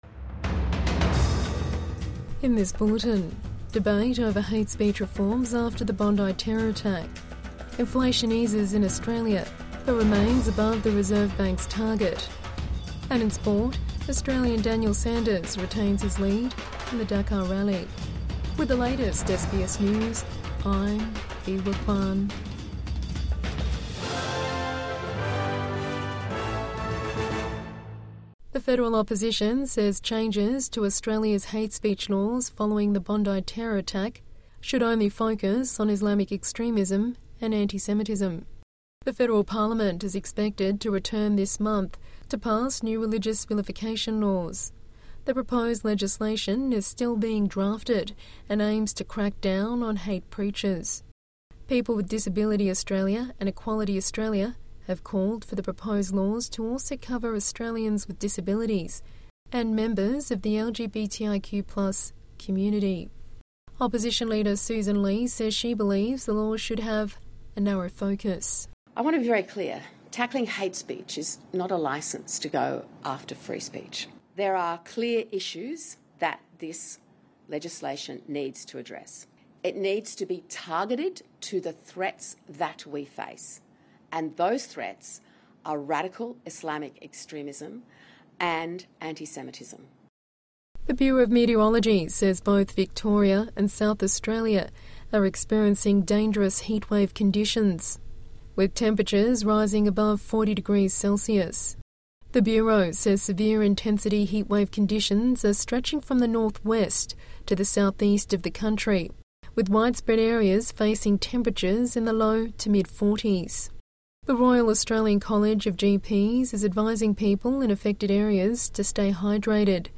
Debate over hate speech reforms after Bondi terror attack | Evening News Bulletin 7 January 2026